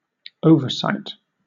Ääntäminen
Ääntäminen Southern England Tuntematon aksentti: IPA : /ˈoʊvə(ɹ)ˌsaɪt/ Haettu sana löytyi näillä lähdekielillä: englanti Käännöksiä ei löytynyt valitulle kohdekielelle. Määritelmät Verbi (ambitransitive) To cite too much.